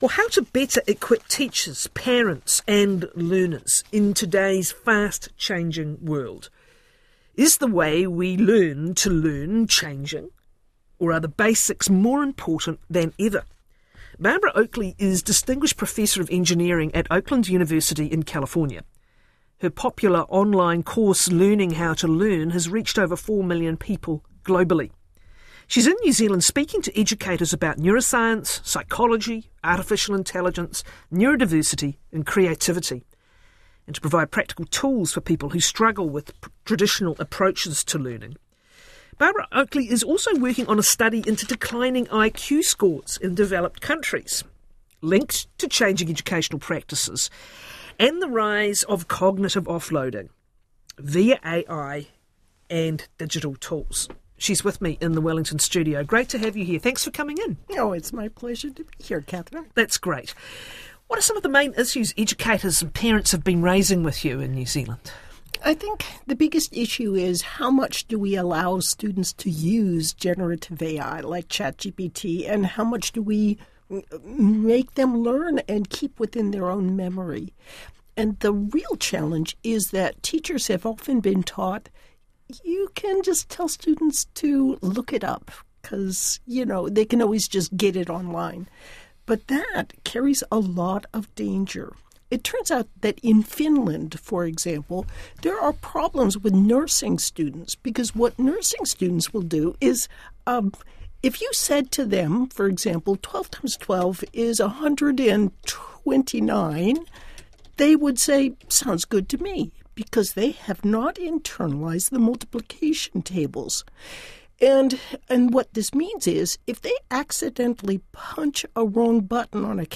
Dr Barbara Oakley talked to Kathryn Ryan on RNZ's Nine to Noon about the critical importance of memory, learning, and cognitive development in an era of artificial intelligence.